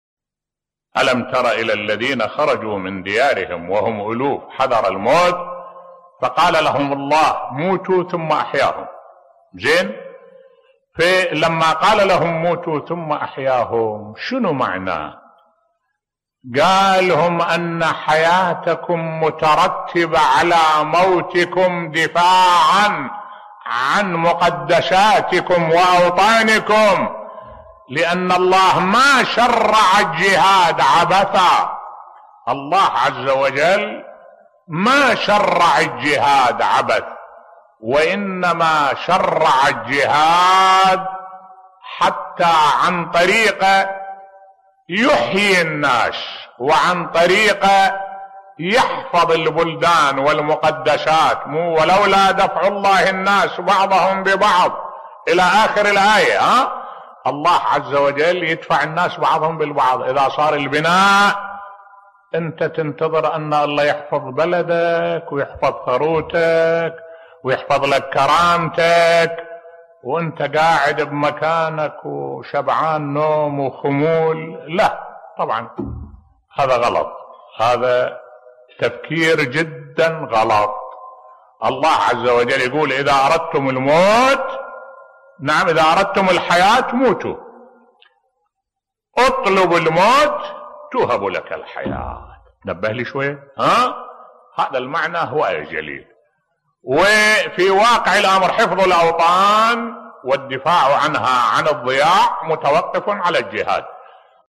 ملف صوتی لماذا شرع الله الجهاد بصوت الشيخ الدكتور أحمد الوائلي